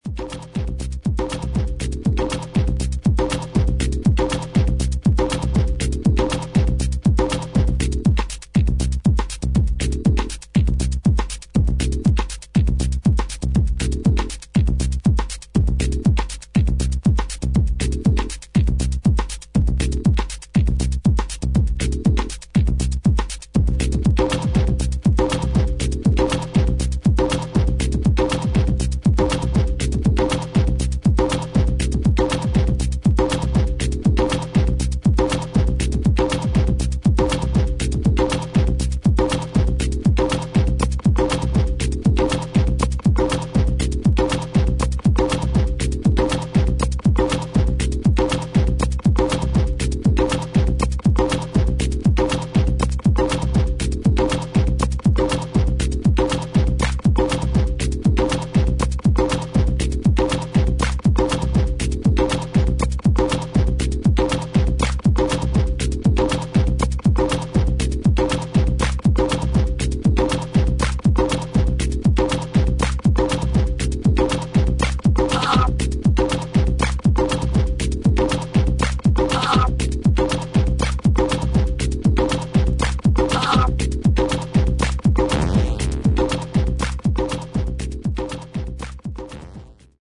低音の音圧の野太さにも驚かされる一枚。